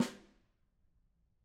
Snare2-HitSN_v5_rr2_Sum.wav